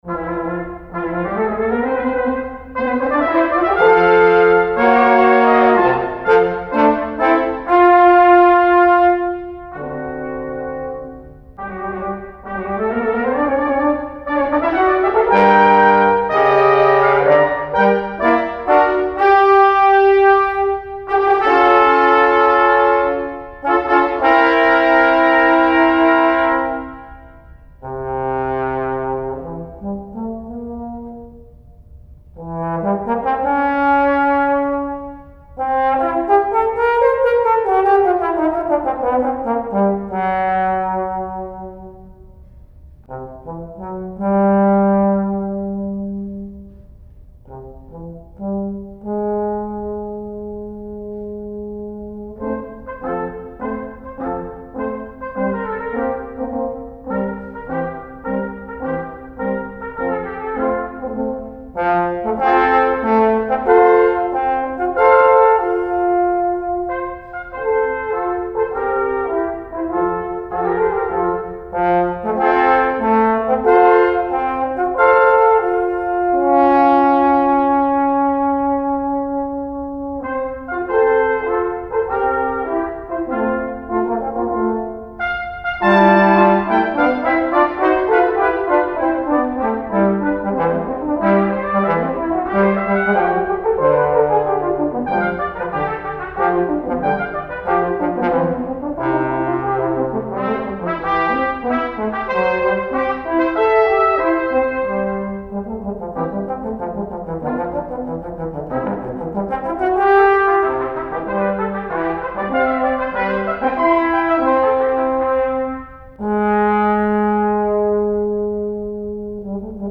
trumpet
trombone